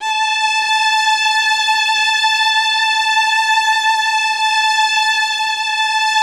MELLOTRON.15.wav